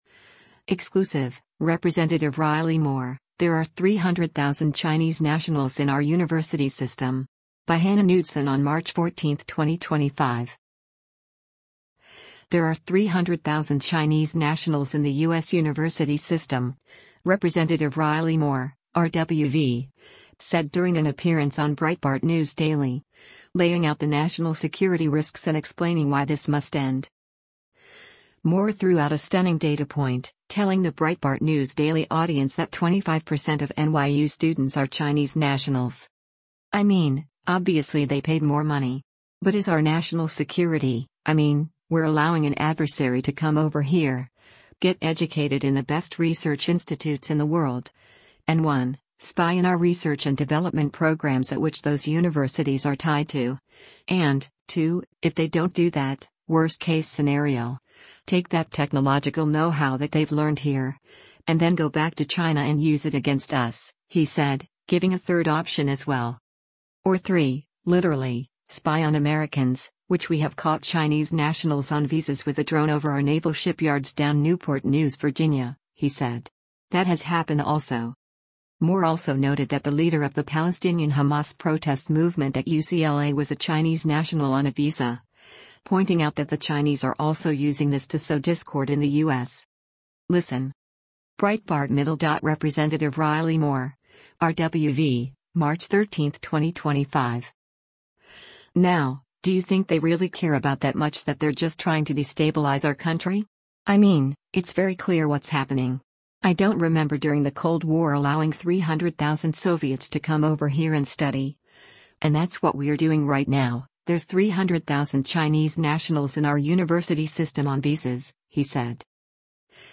There are 300,000 Chinese nationals in the U.S university system, Rep. Riley Moore (R-WV) said during an appearance on Breitbart News Daily, laying out the national security risks and explaining why this must end.
Breitbart News Daily airs on SiriusXM Patriot 125 from 6:00 a.m. to 9:00 a.m. Eastern.